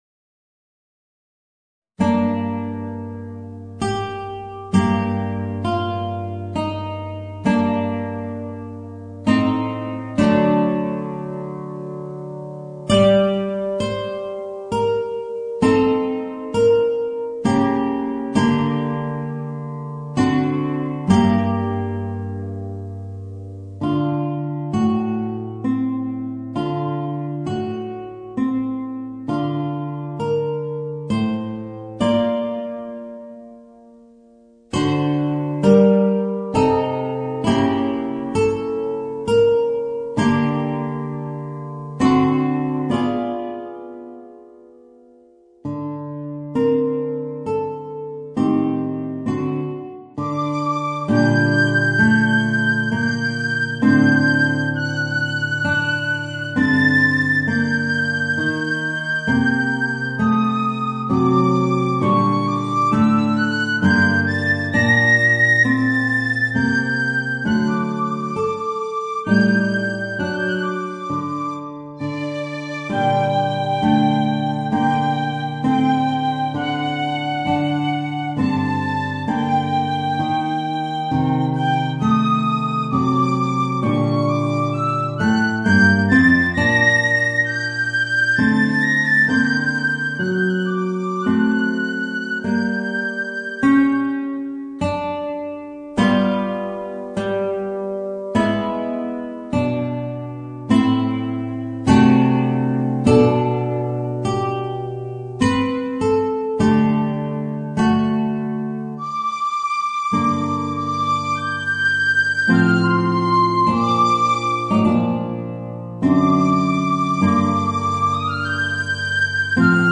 Voicing: Piccolo and Guitar